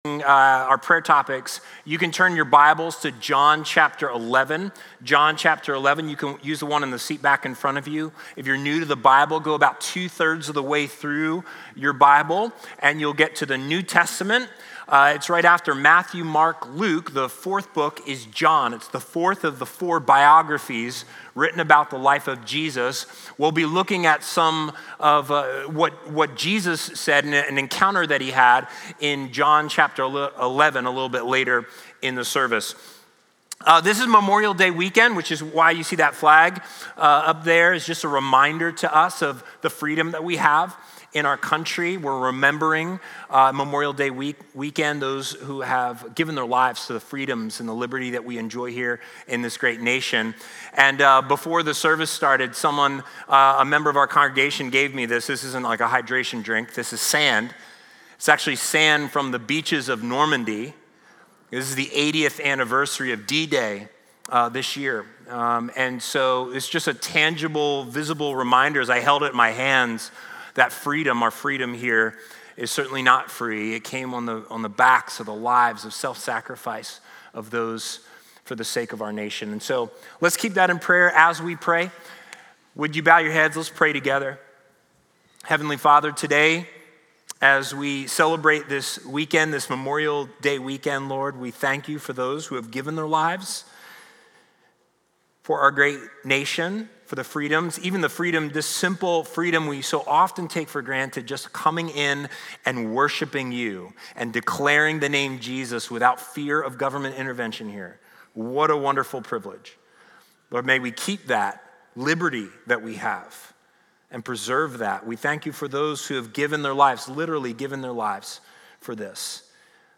The Only Hope for Suffering Sermon Notes Facebook Tweet Link Share Link Send Email